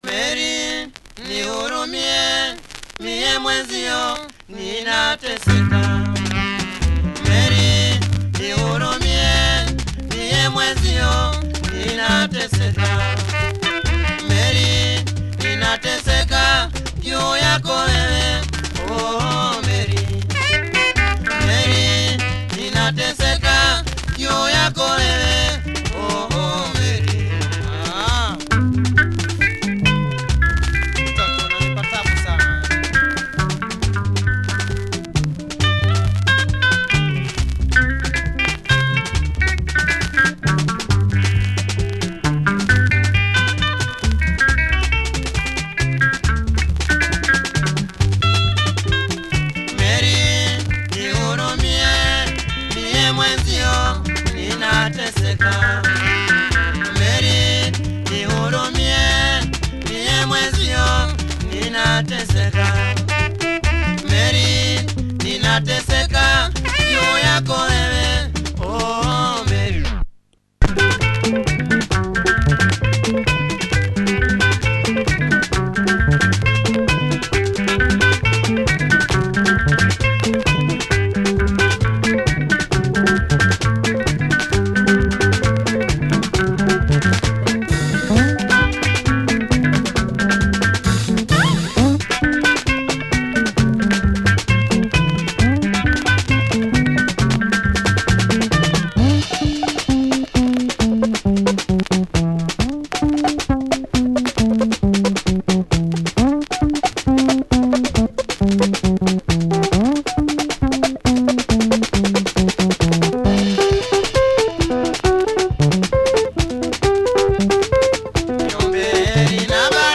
Nice stick drums in Cavacha style and nice sax.